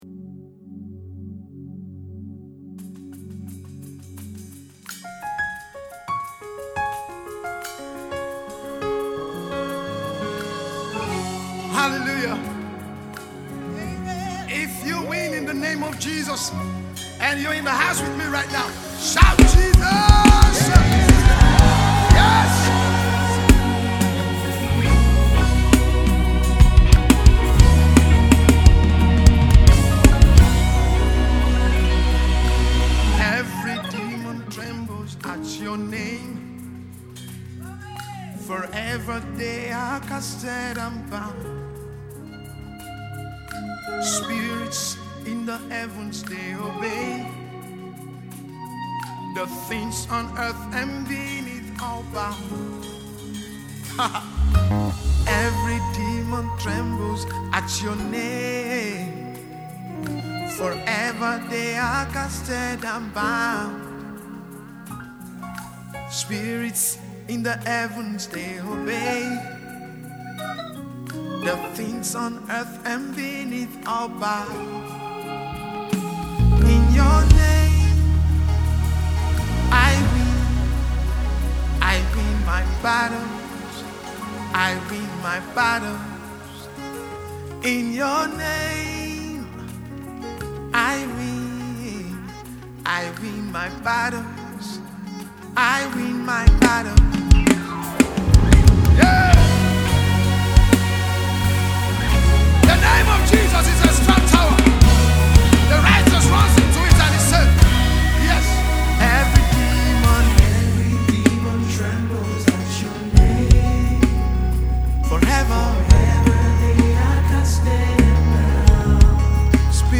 writer and a gospel music minister.